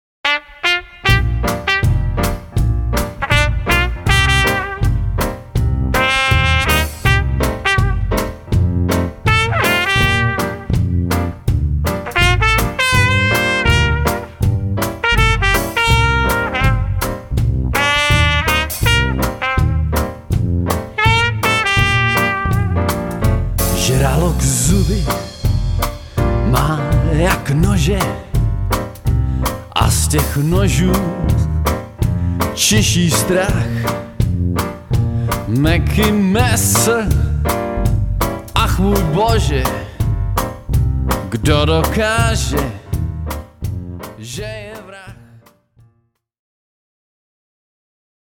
Milujeme jazz a swing -